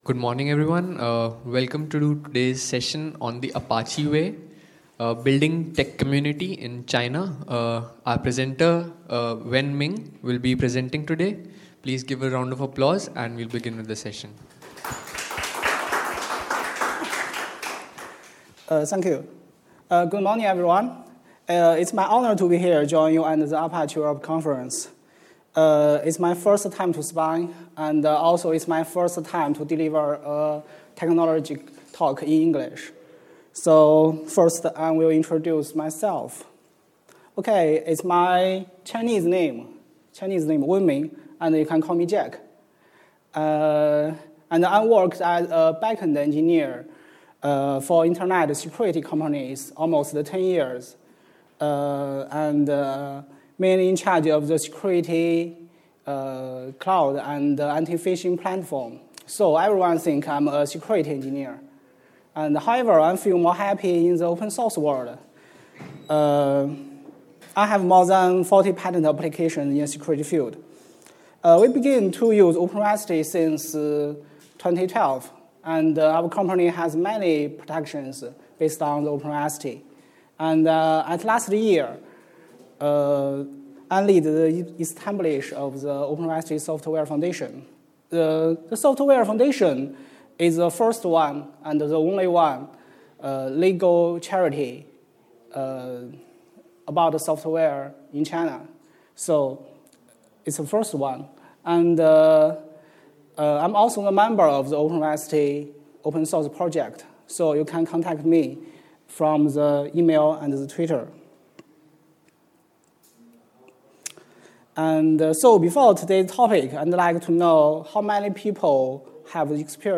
ApacheCon Seville 2016 -The Apache Way